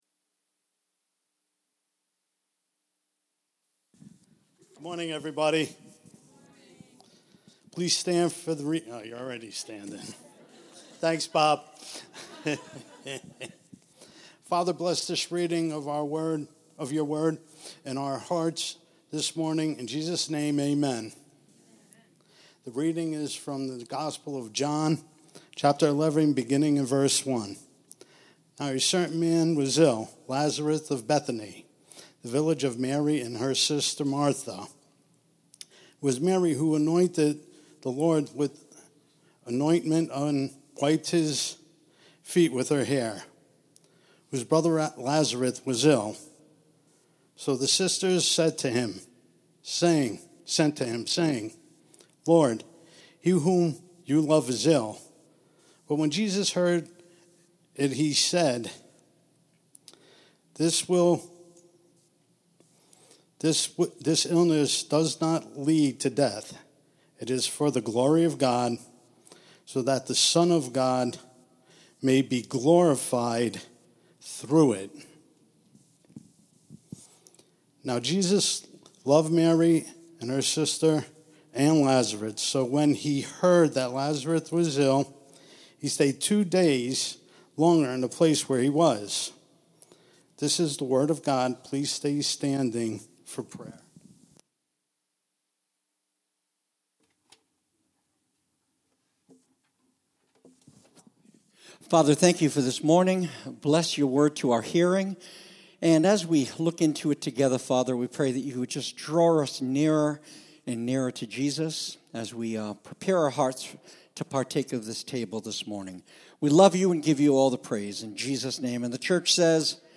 The 2nd John 10 Sermonette, Part 2: ‘One Flock, One Shepherd’ (John 10.16-21)